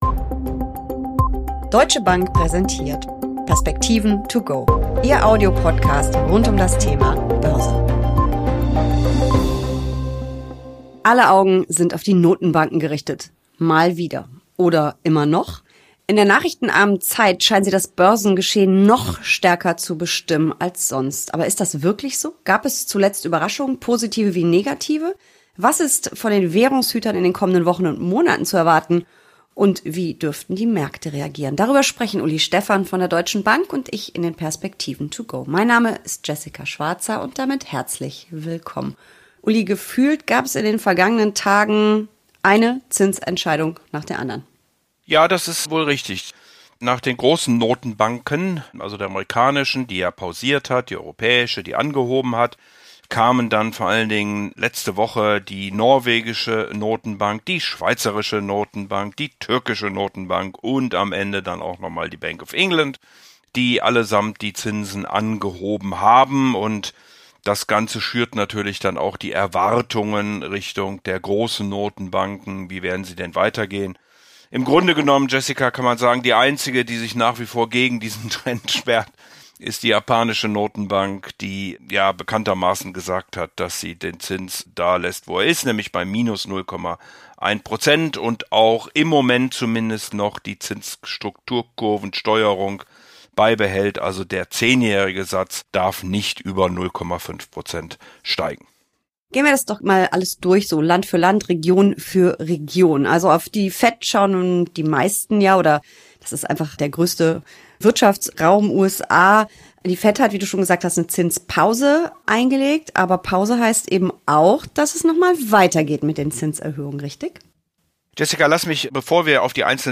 Schnell, pragmatisch und auf den Punkt.
im Gespräch